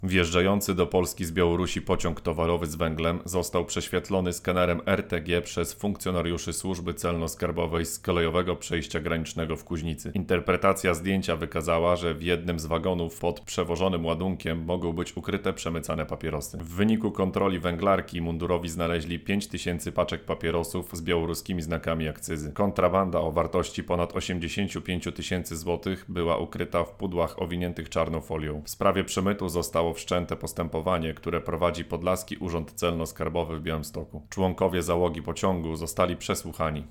wypowiedź